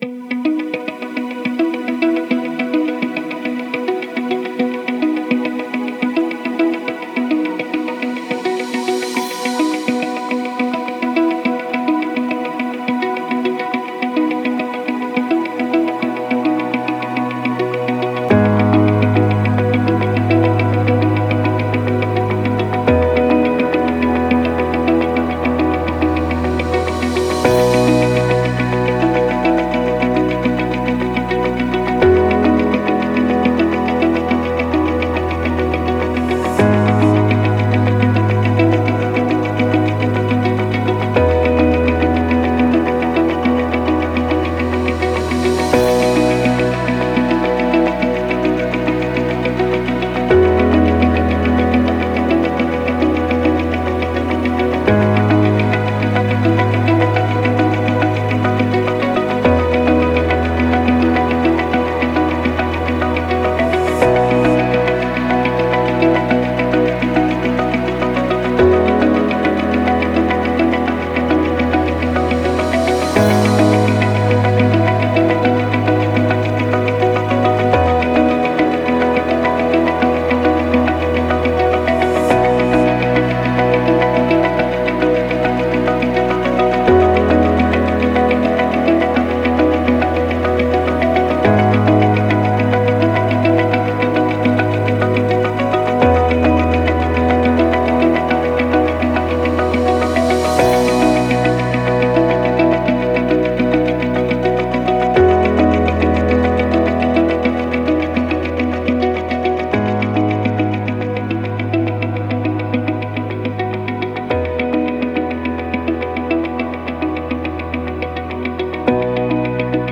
Moment-of-Inspiration-Without-Drums.wav